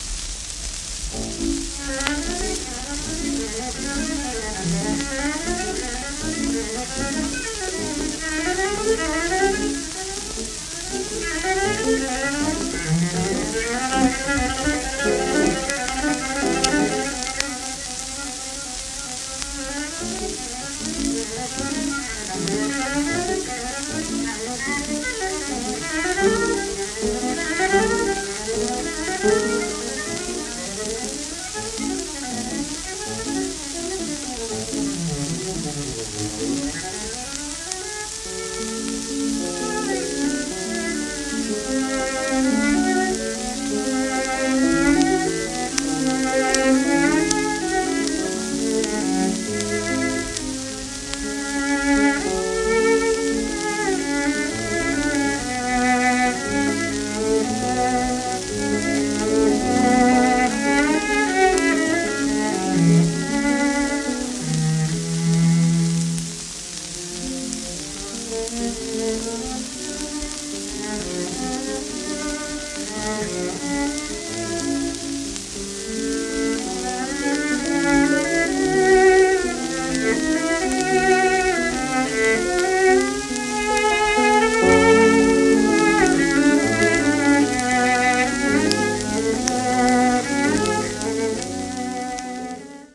w/ピアノ